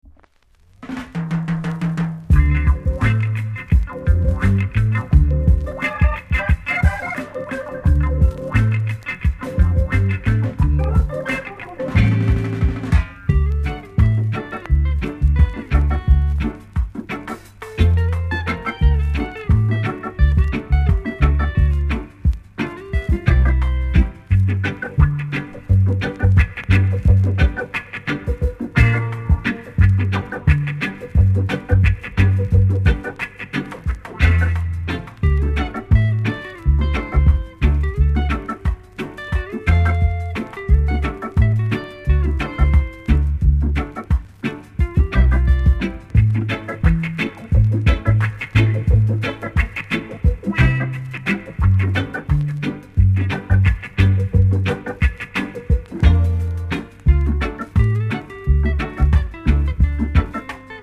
コメント FUNKY INST!!